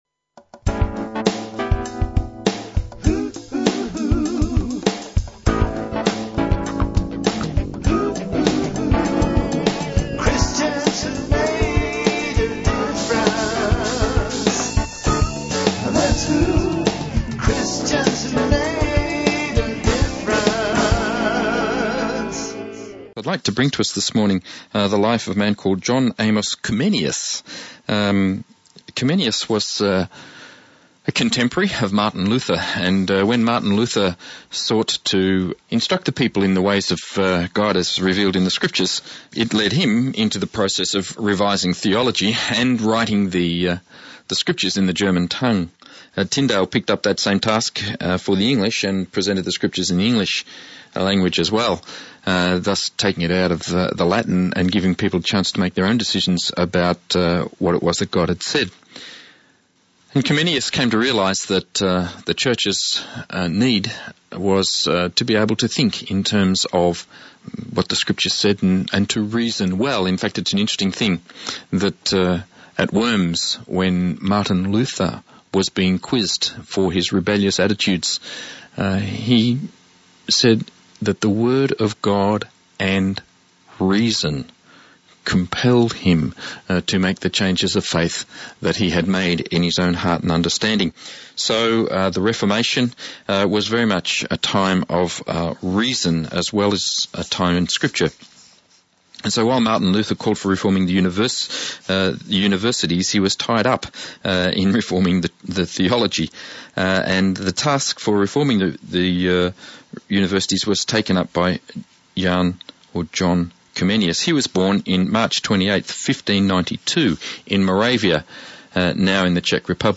john comeniusListen again to the story of John Comenius, broadcast on Southern FM 88.3 on “Songs of Hope” on 17 November 2013. He changed the way the West tackled education.